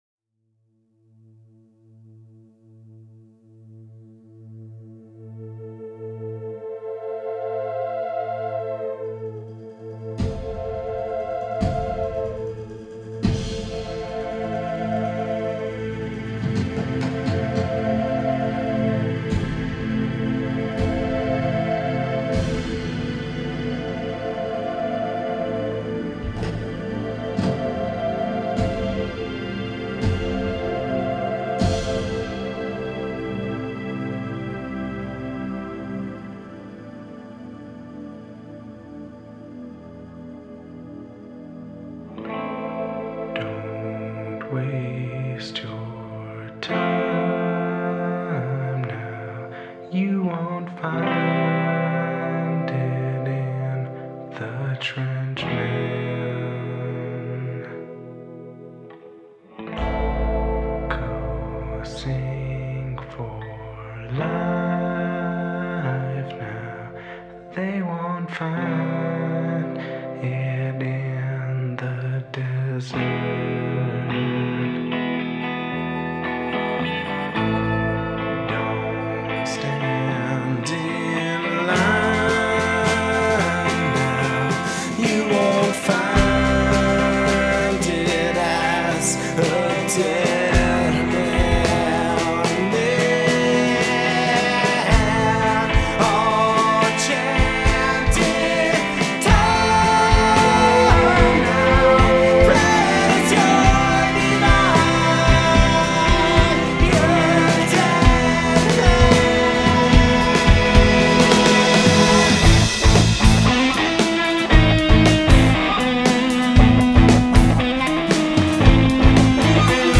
psychedelic and dreamy